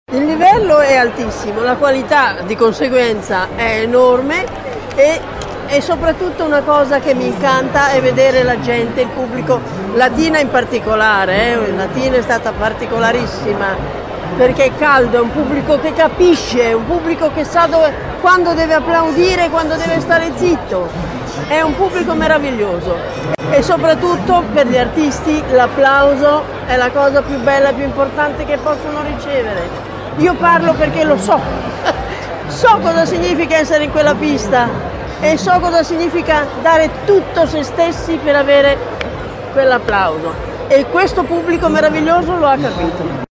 Nella giuria di grandi esperti del settore scelta dall’organizzazione, vere e proprie star del Circo come Liana Orfei che ha voluto ringraziare il pubblico di Latina.
liana-orfei.mp3